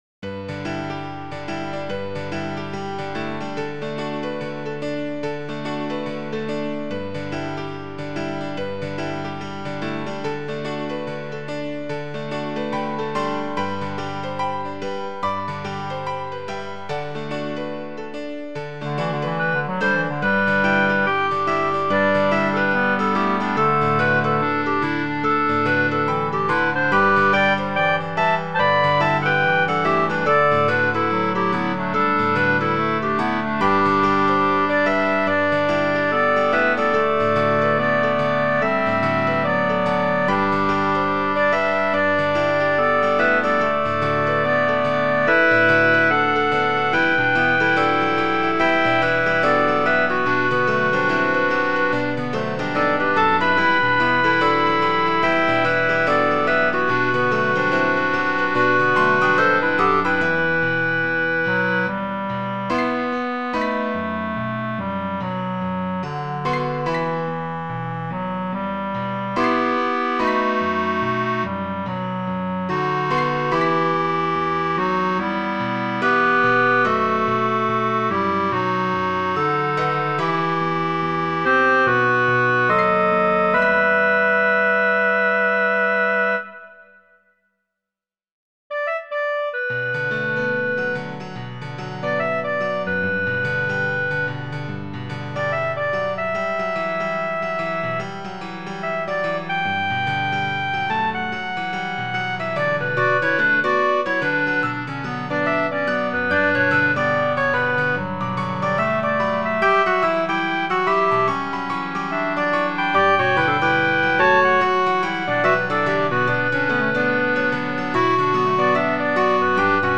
Title Spring Comes Opus # 125 Year 0000 Duration 00:02:42 Self-Rating 4 Description Winter leaves. mp3 download wav download Files: wav mp3 Tags: Trio, Piano, Clarinet Plays